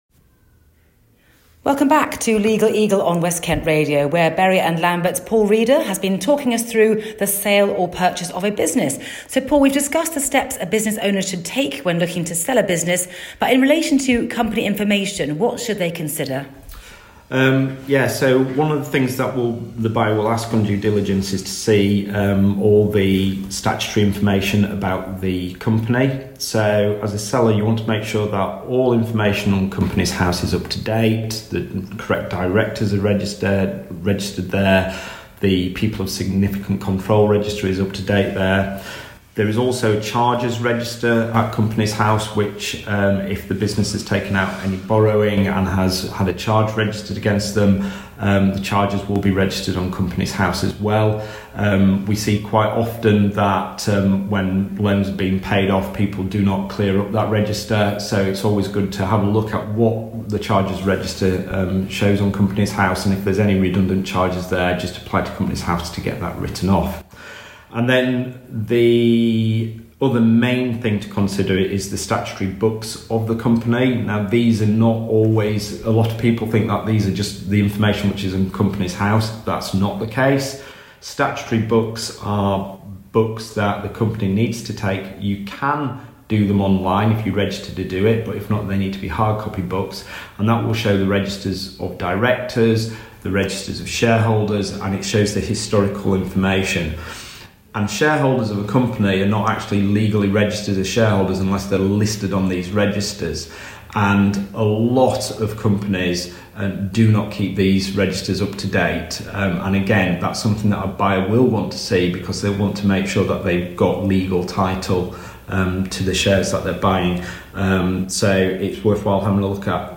More from INTERVIEW REPLAYS